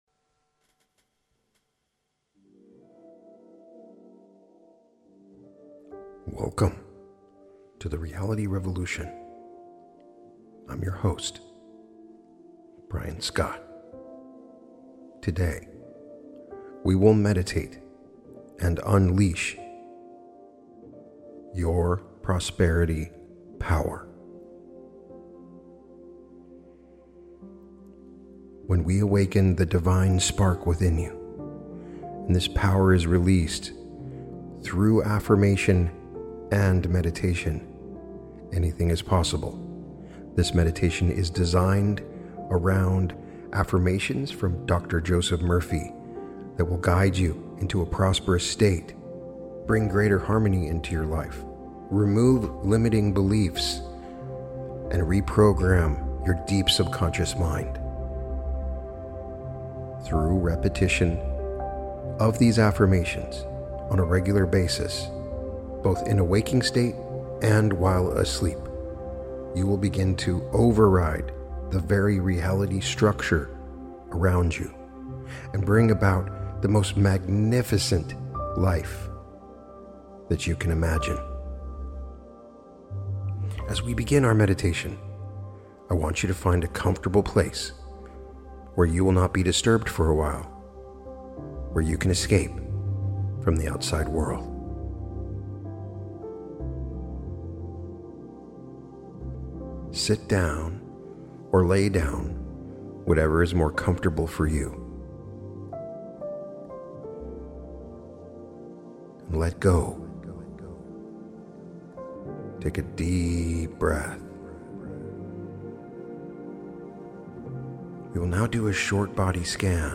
Guided Meditation - Unleash Your Prosperity Power (Joseph Murphy)